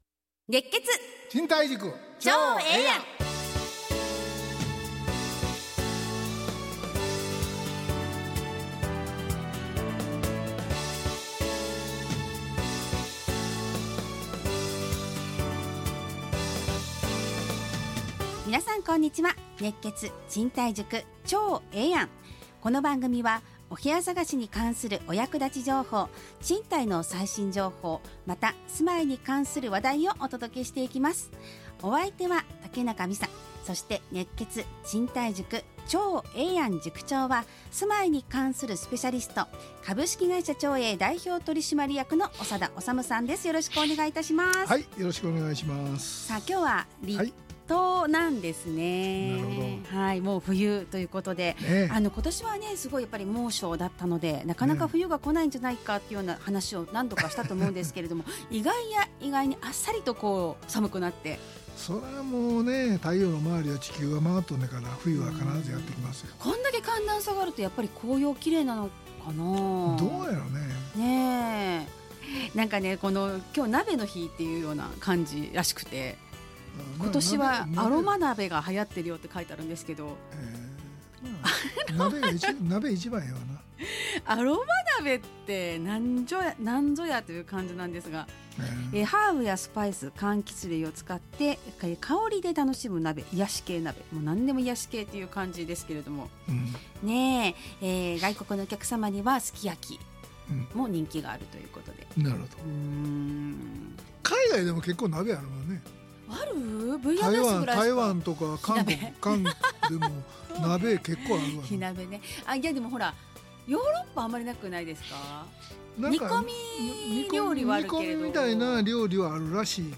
ラジオ放送 2025-11-07 熱血！